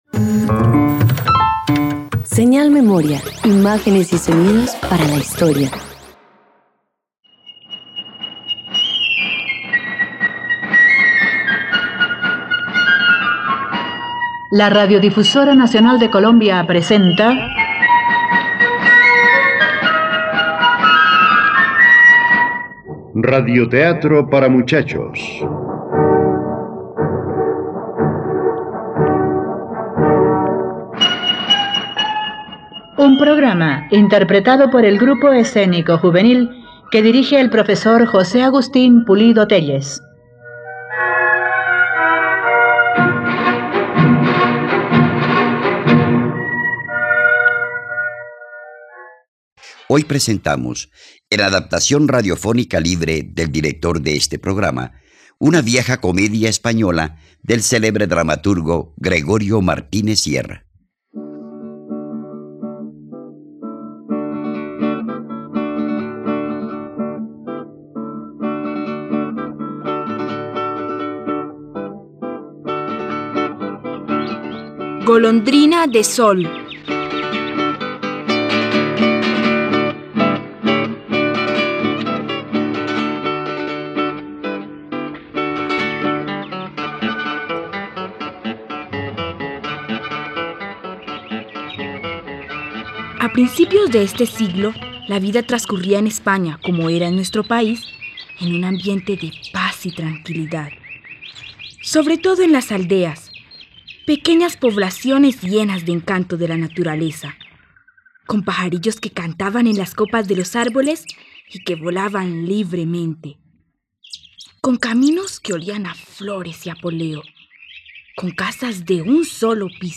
Golondrinas del sol - Radioteatro dominical | RTVCPlay